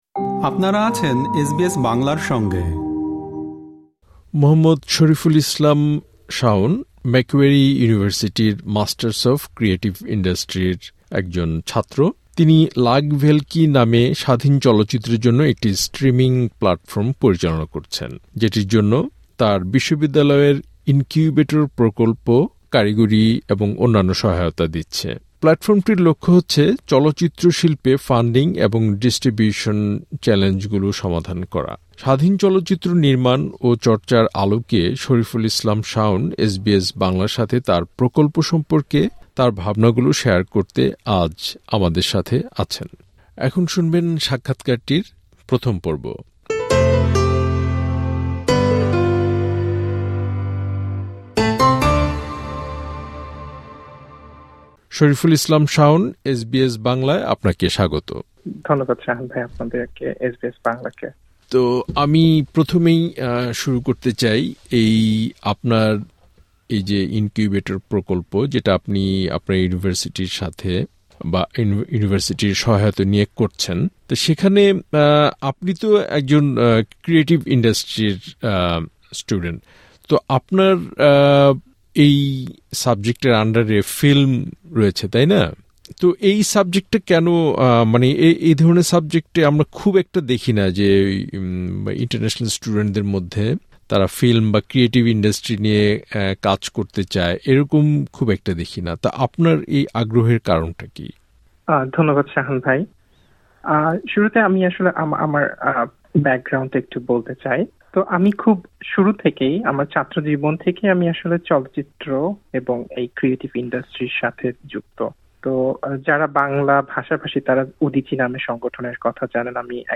এখানে প্রকাশিত হলো তার সাক্ষাৎকারটির ১ম পর্ব - শুনতে ক্লিক করুন উপরের অডিও প্লেয়ারে।